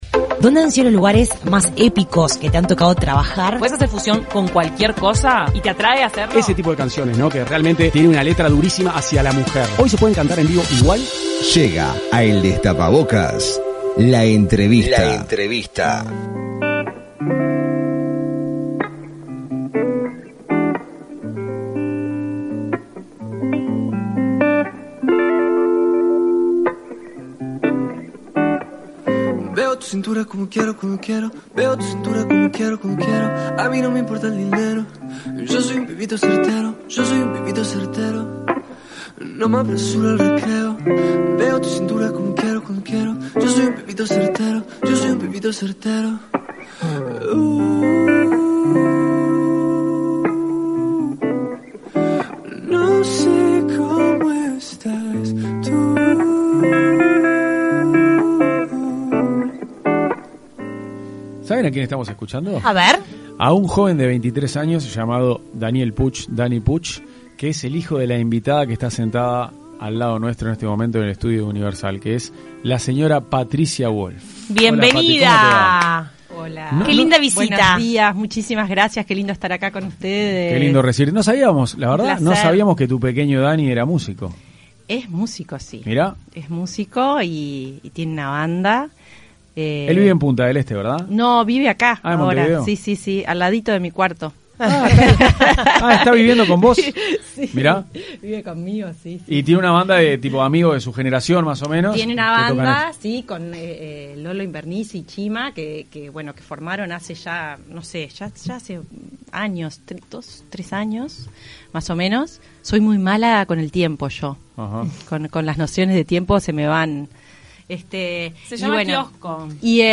Esta mañana, Patricia Wolf fue entrevistada por el equipo de El Destapabocas y entre otros temas, conversó acerca de su vínculo con hijo, su rol como investigadora en el programa «La Máscara» en canal 12 y sobre su situación sentimental actual.
Escuchá la entrevista completa de El Destapabocas a Patricia Wolf: